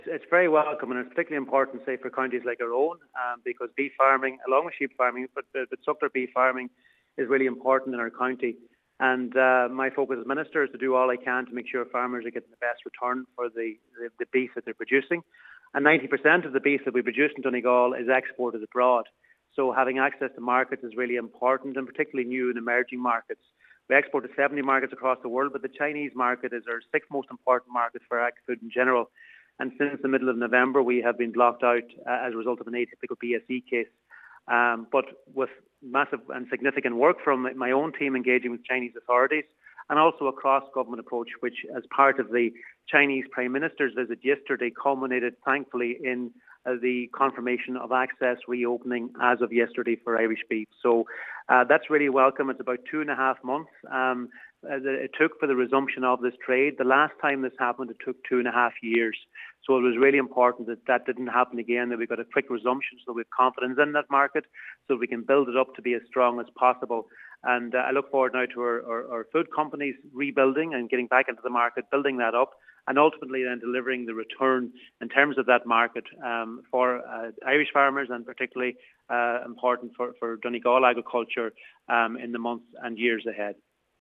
Minister McConalogue says a quick resolve of the issue was important: